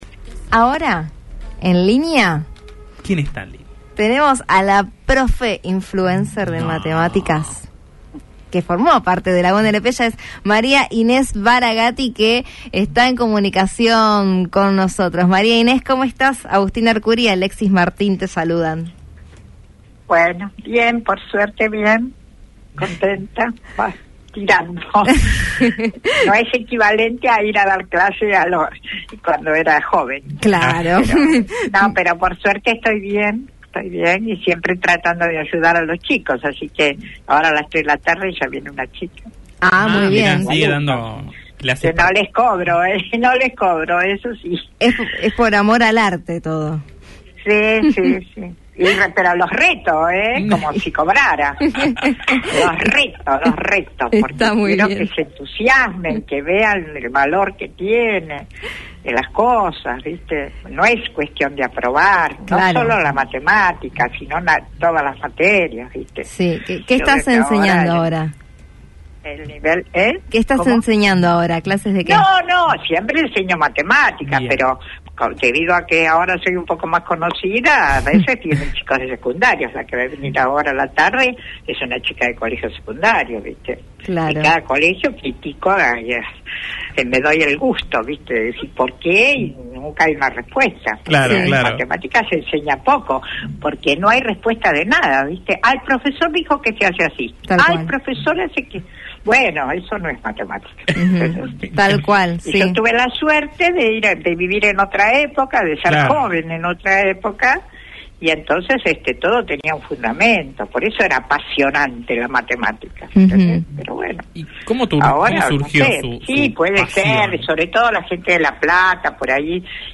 En diálogo con el programa Después del Mediodía, por Radio La Plata 90.9 FM, la profesora compartió su visión sobre la materia y la pasión y el compromiso que la llevan a continuar dando clases particulares incluso de forma gratuita.